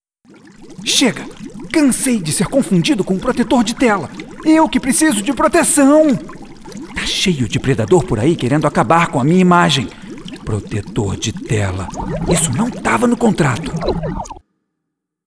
Masculino
Voz Jovem 00:15
• Tenho voz leve e versátil, e interpretação mais despojada.